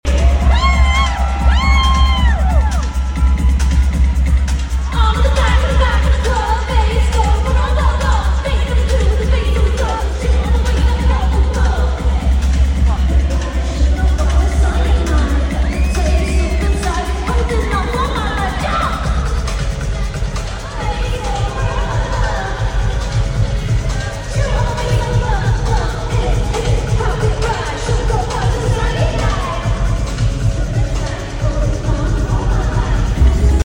United Center, Chicago